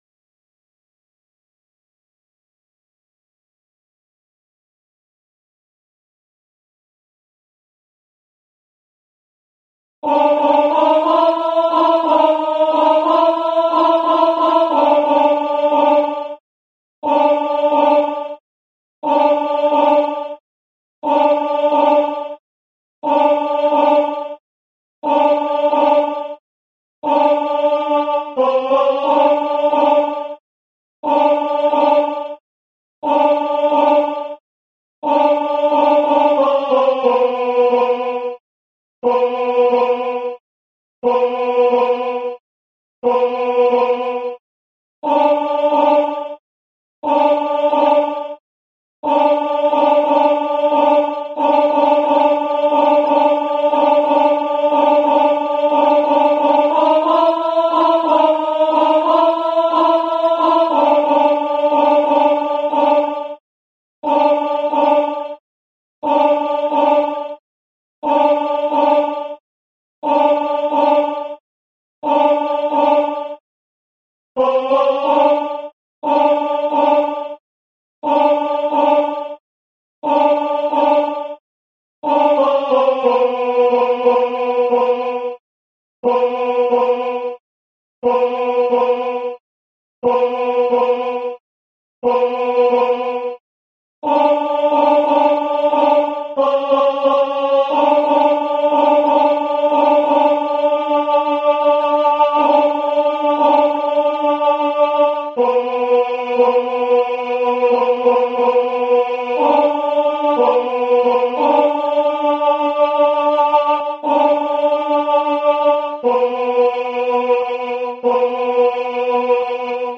Coro musica afroamericana, blues, swing, spirituals - Bologna
i-wish-was-single-again-versione-definitiva-contralti-1.mp3